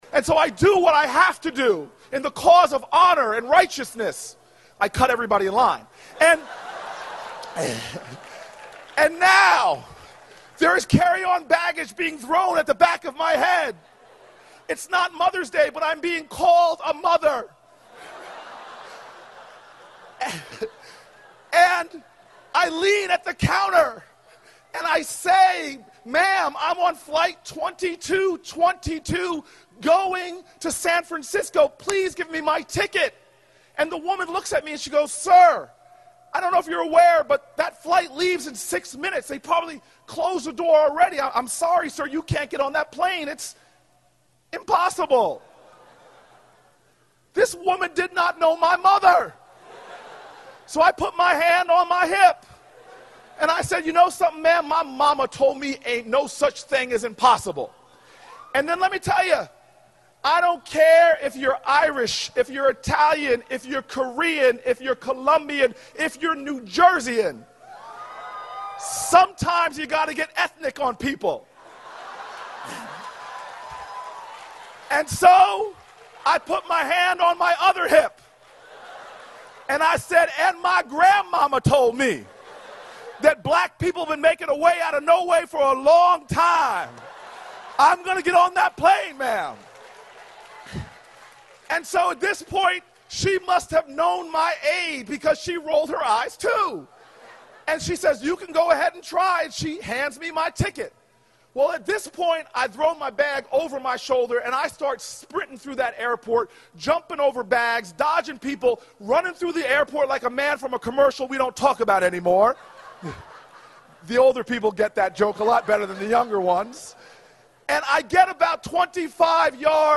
公众人物毕业演讲第441期:科里布克2013年耶鲁大学(9) 听力文件下载—在线英语听力室